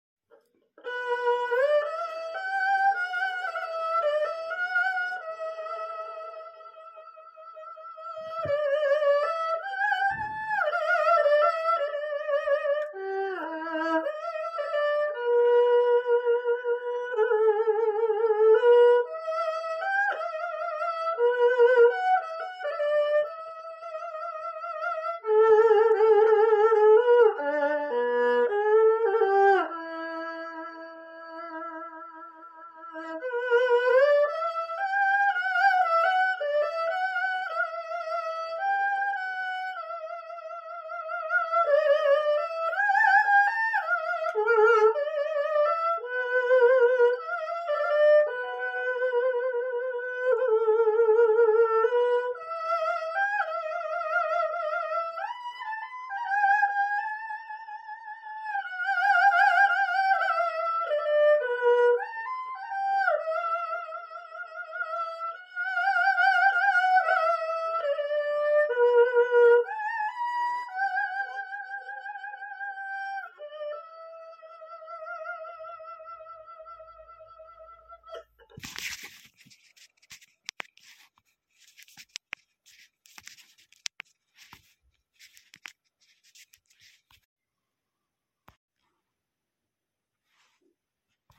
二胡伴奏 9